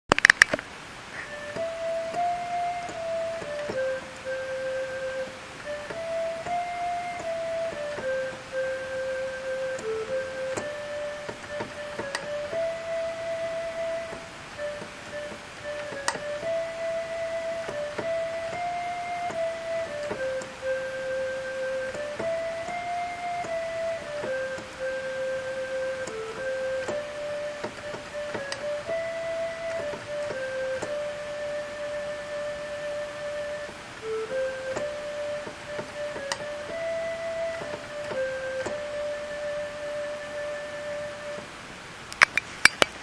すいません。録音機器の調子が悪くて、携帯録音です。